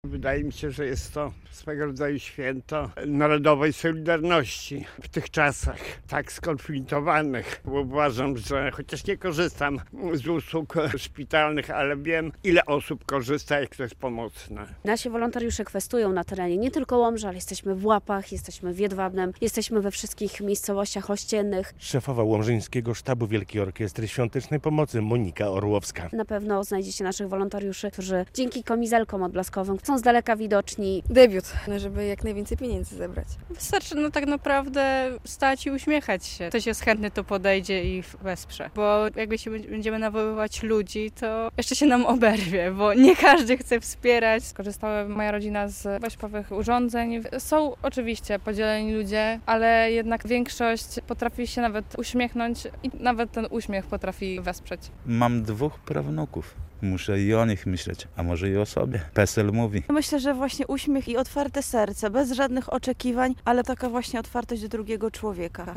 33 Finał Wielkiej Orkiestry Świątecznej Pomocy w Łomży - relacja